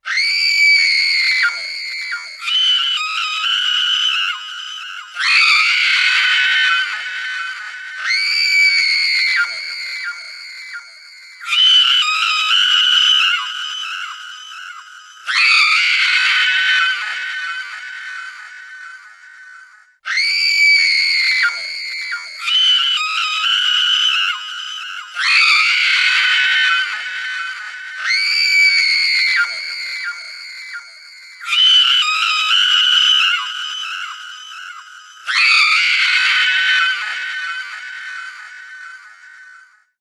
キャー！キャー！キャー！キャー！キャー！キャー！。